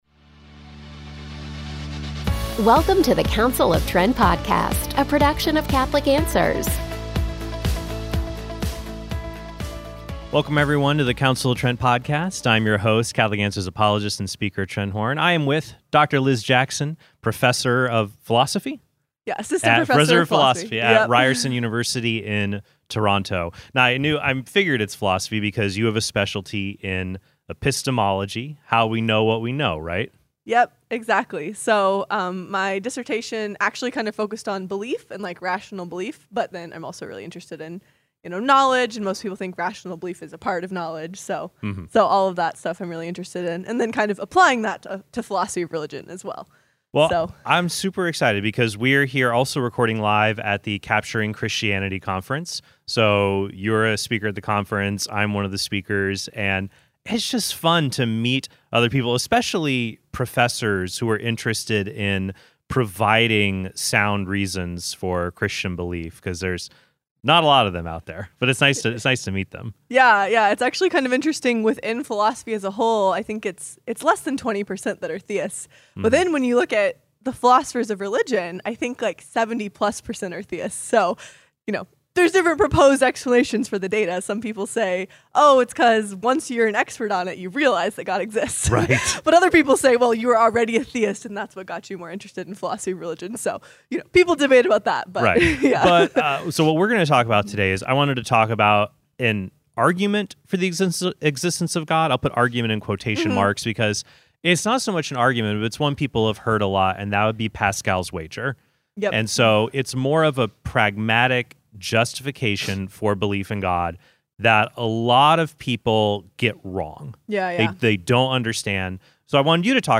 So, you’re a speaker at the conference, I’m one of the speakers; and it’s just fun to meet other people, especially professors who are interested in providing sound reasons for Christian belief.